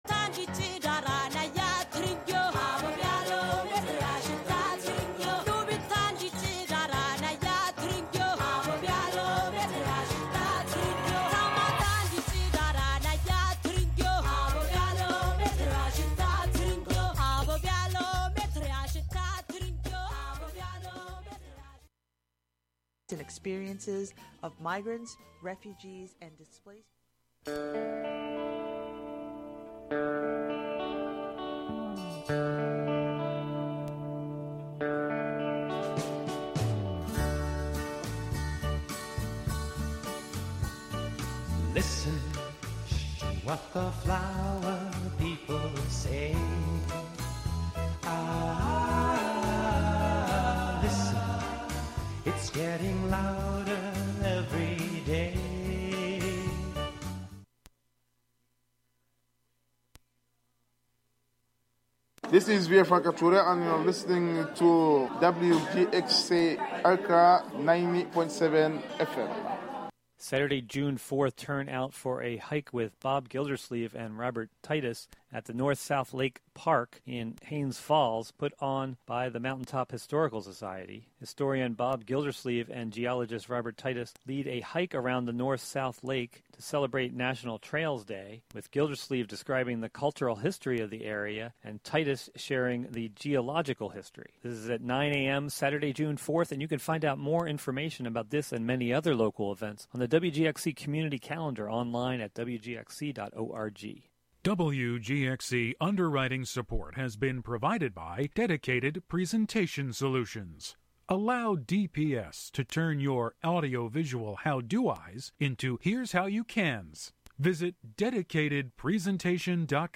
Classic R&B, Funk: May 30, 2022: 11am - 11:59 am